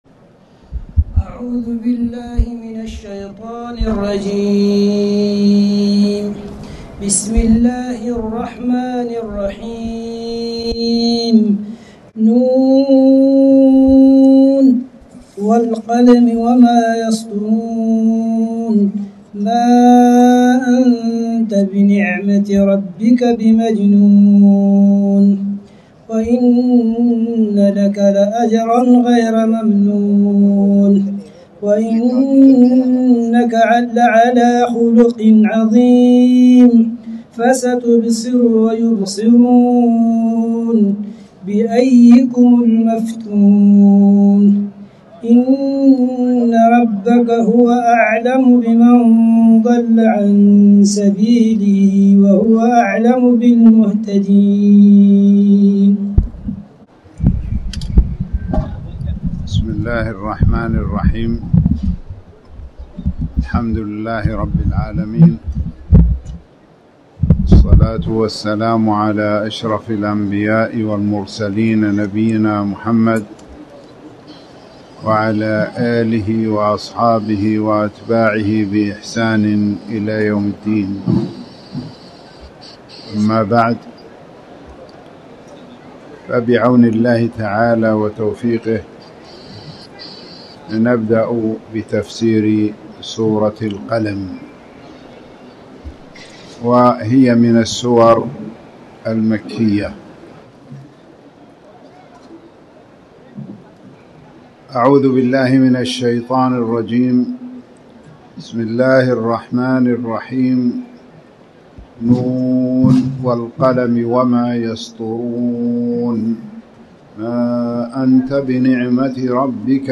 تاريخ النشر ٢ ربيع الأول ١٤٣٩ هـ المكان: المسجد الحرام الشيخ